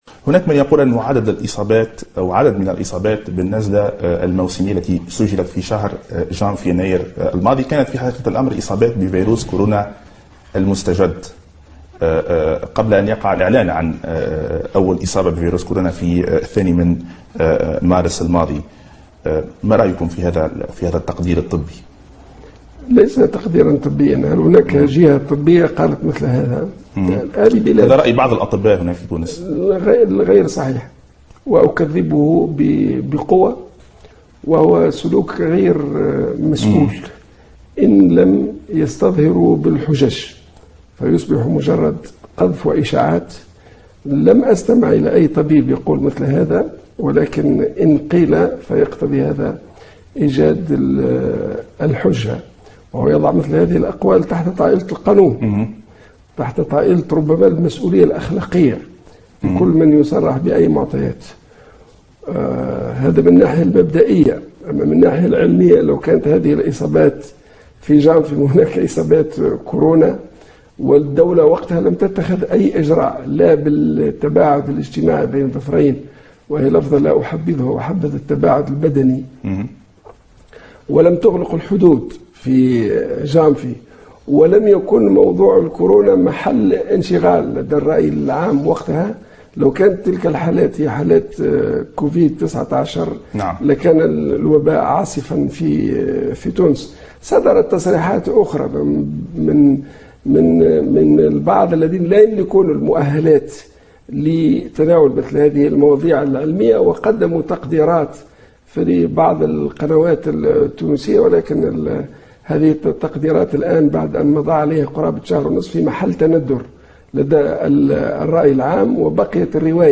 علق وزير الصحة عبد اللطيف المكي خلال استضافته في قناة "التلفزيون العربي" على تصريحات مصادر طبية، قالت فيها إن فيروس كورونا انتشر في تونس منذ آواخر شهر ديسمبر 2019، تزامنا مع انتشارها في الصين، وبلغ ذروته خلال شهر جانفي 2020 دون أن تتفطن السلطات الصحية إلى ذلك.